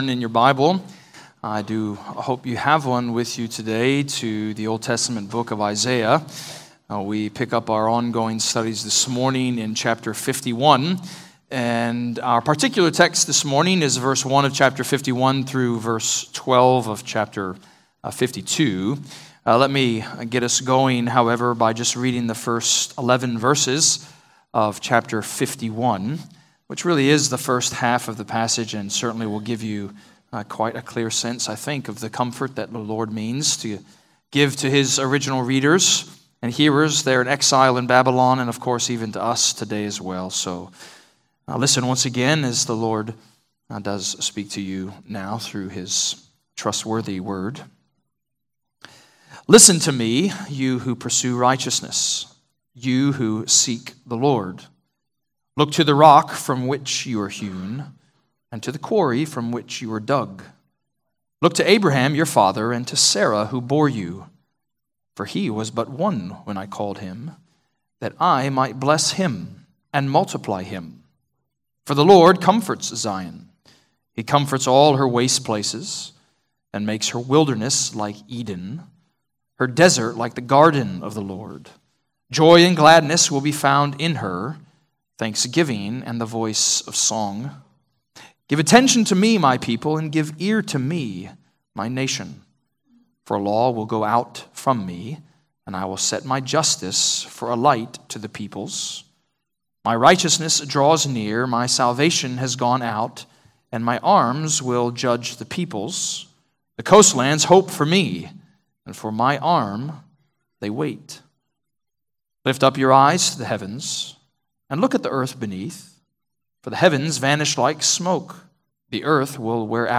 Redeemer Presbyterian Church: Sermon Audio podcast
Download sermons from Redeemer Presbyterian Church in McKinney, TX.